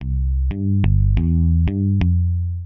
摇滚精选 90 Bpm C
描述：用果味循环制作的较硬的摇滚低音线
Tag: 90 bpm Rock Loops Bass Loops 459.52 KB wav Key : C